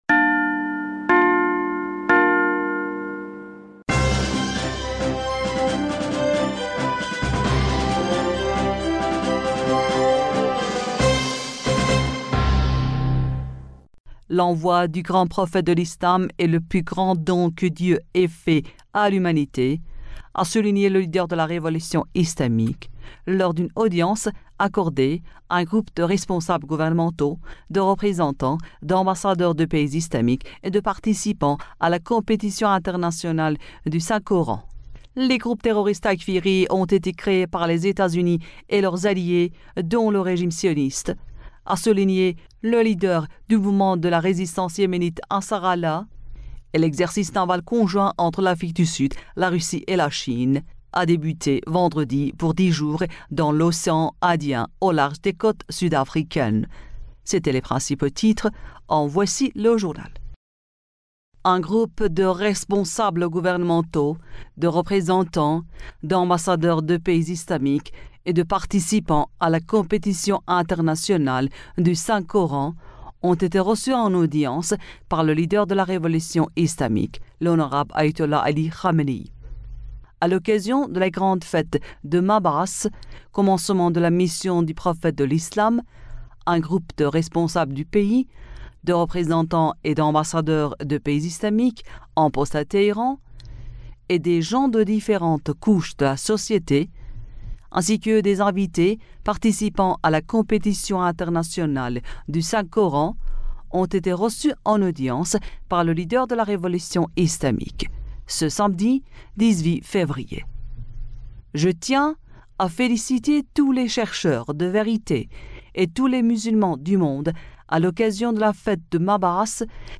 Bulletin d'information du 18 Février